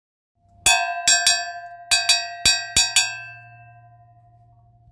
ドゥンドゥン鉄ベル 大
大きいサイズの手作りの鉄製ベル。
ワイルドな音色の鉄楽器です。
素材： 鉄